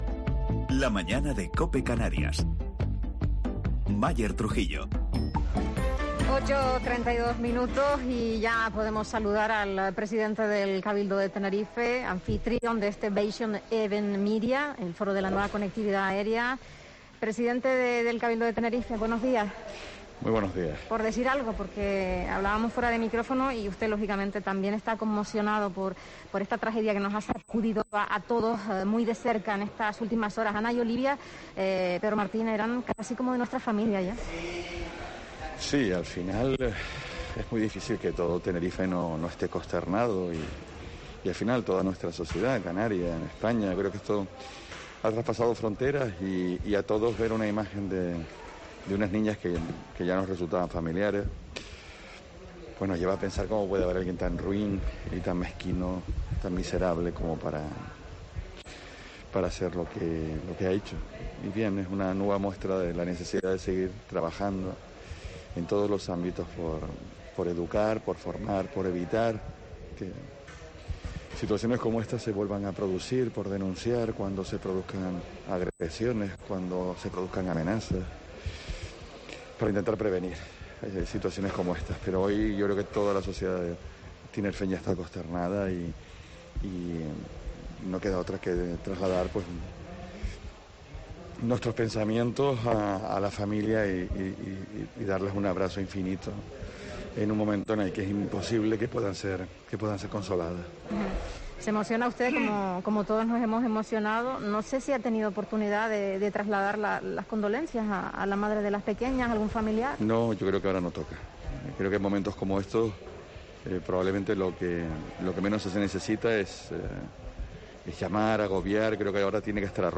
Pedro Martín, presidente del Cabildo de Tenerife